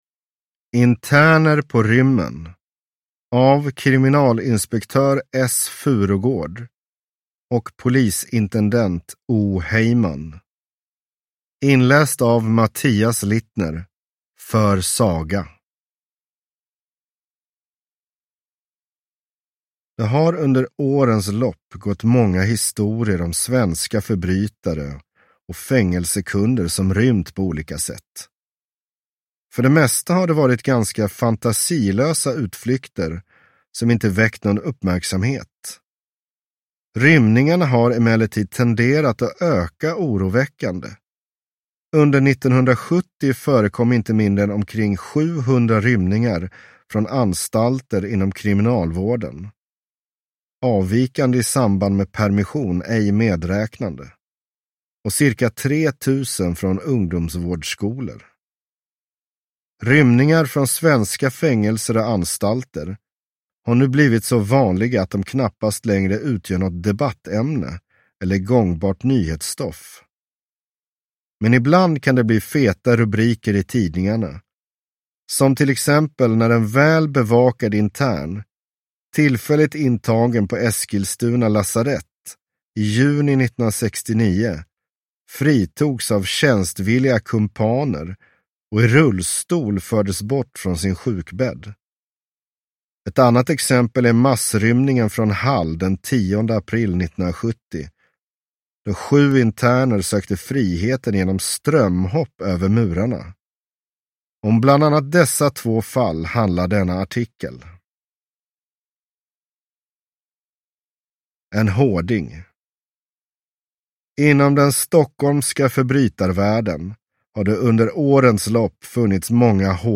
Interner på rymmen (ljudbok) av Svenska Polisidrottsförlaget